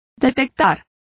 Complete with pronunciation of the translation of detect.